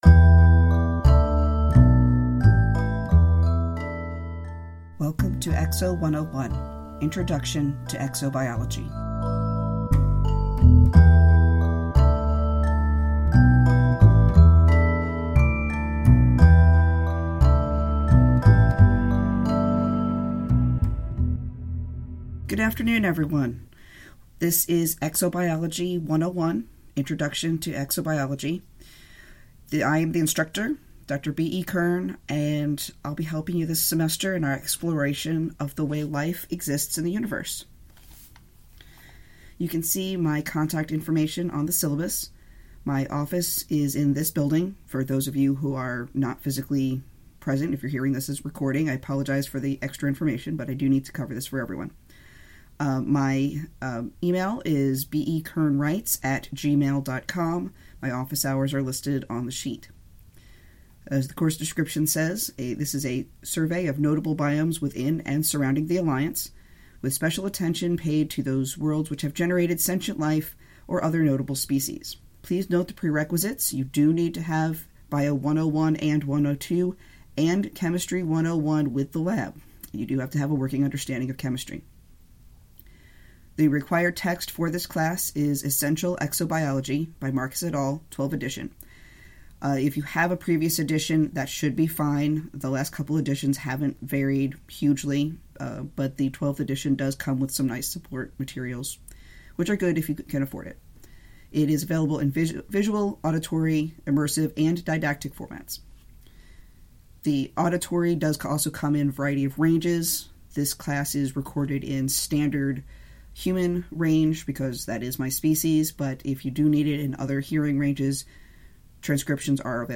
It's a fictionally educational lecture series on the biology of imagined worlds.